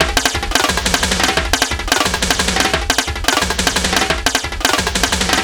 ___TEK TOM 1.wav